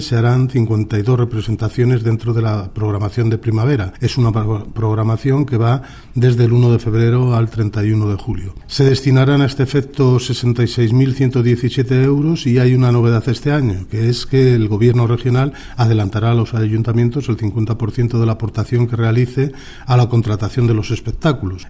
El director provincial de Educación, Cultura y Deportes en Guadalajara, Faustino Lozano, habla de la programación de primavera de la Red de Artes Escénicas y Musicales en la provincia.